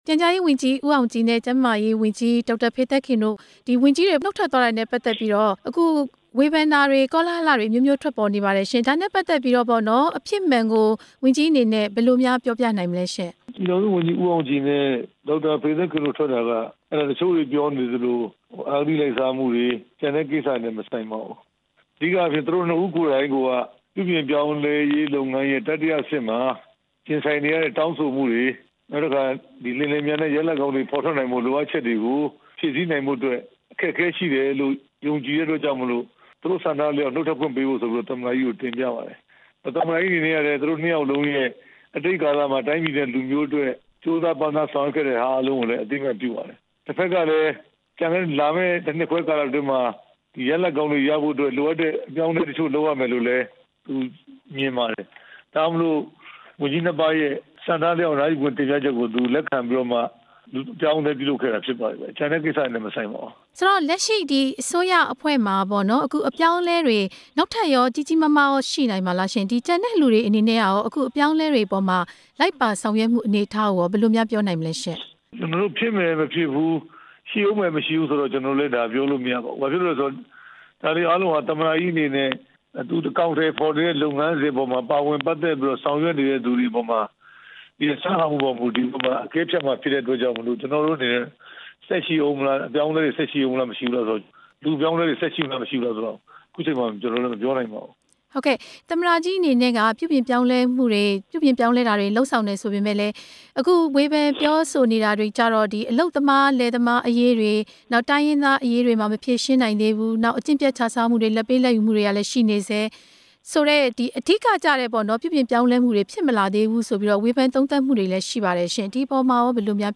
ဦးရဲထွဋ်နဲ့ မေးမြန်းချက်